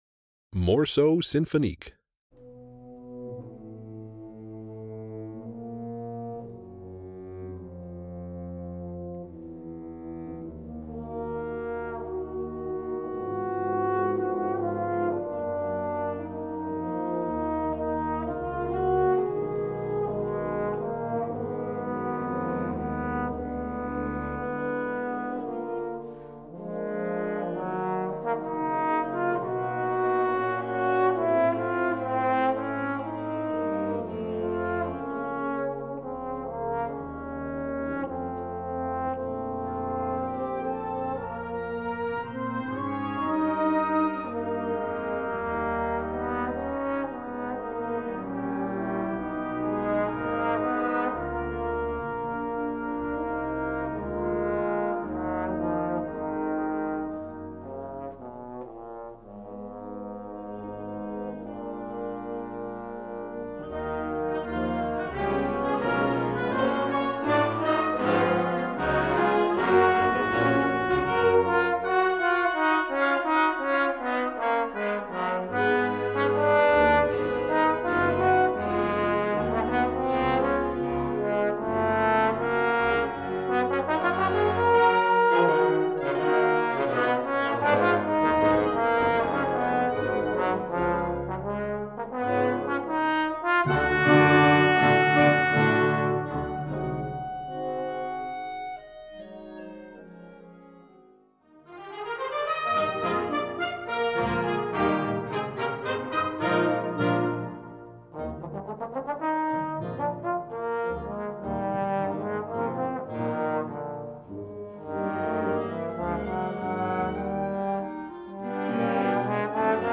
Voicing: Trombone w/ Band